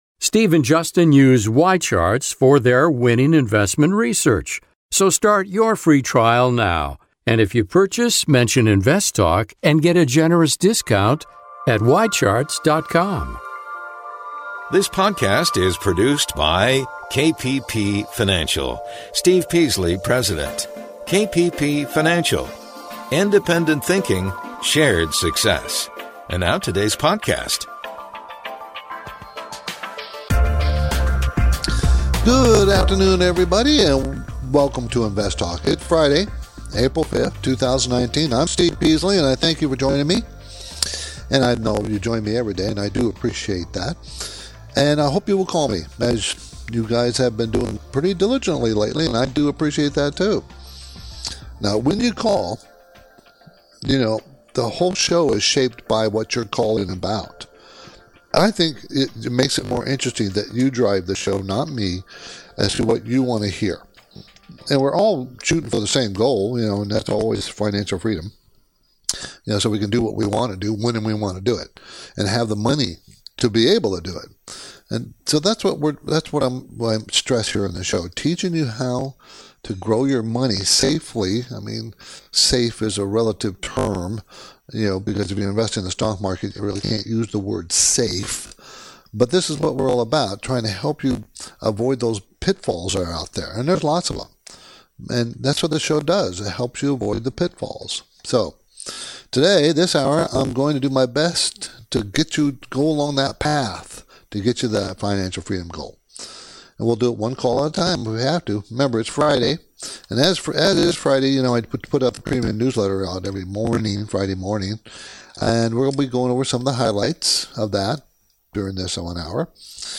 *Podcast Listeners: We are experiencing an intermittent technical issue that may cause the audio stream to break-up or sound distorted.